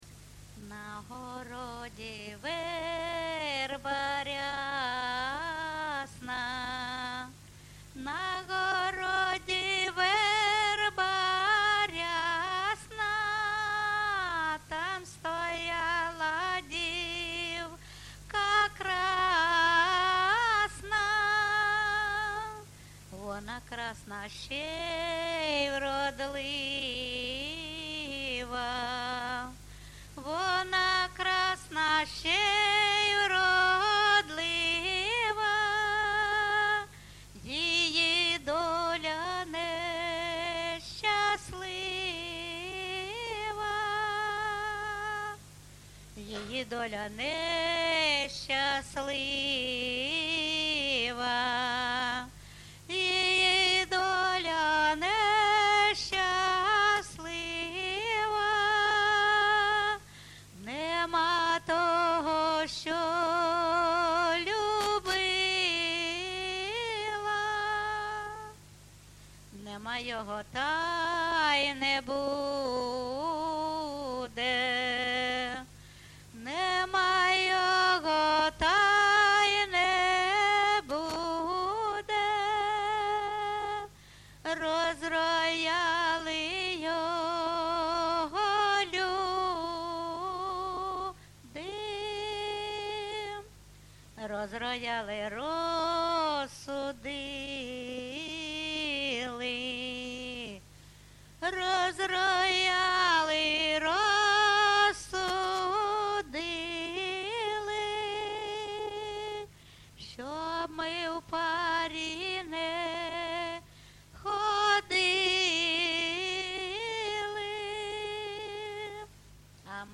ЖанрПісні з особистого та родинного життя
Місце записус-ще Новодонецьке, Краматорський район, Донецька обл., Україна, Слобожанщина